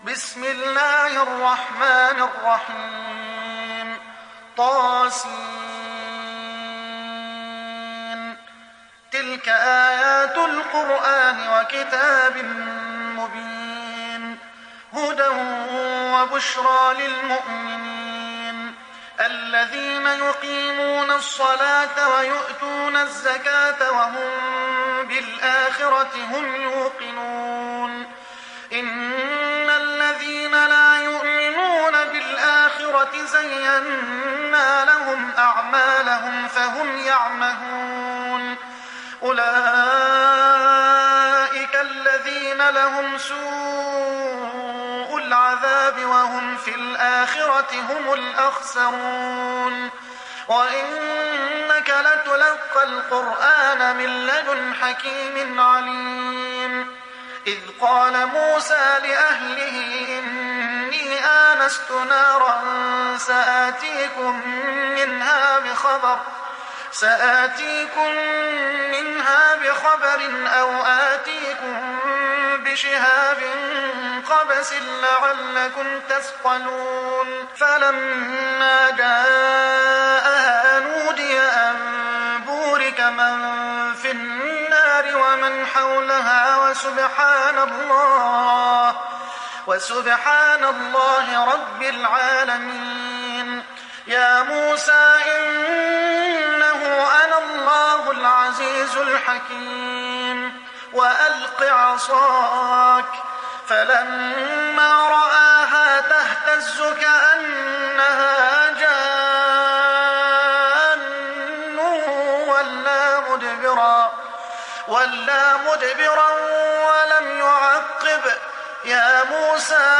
تحميل سورة النمل mp3 بصوت محمد حسان برواية حفص عن عاصم, تحميل استماع القرآن الكريم على الجوال mp3 كاملا بروابط مباشرة وسريعة